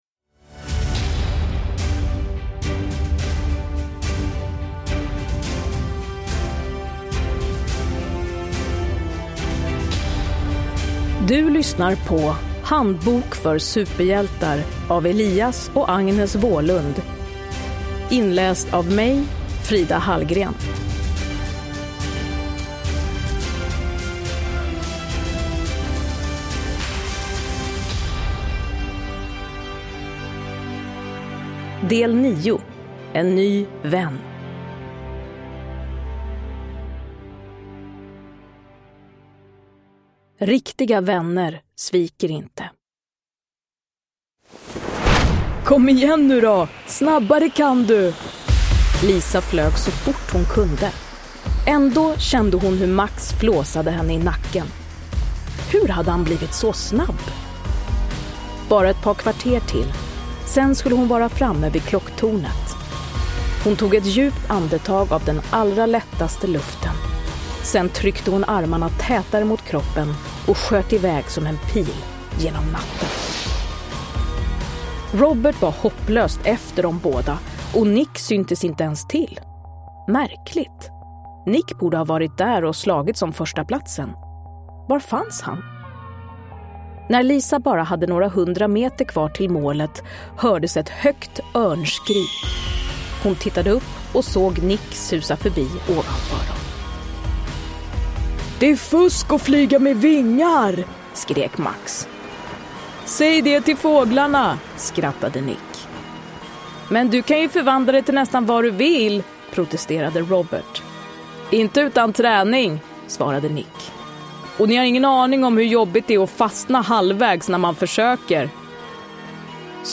Handbok för superhjältar. En ny vän – Ljudbok
Uppläsare: Frida Hallgren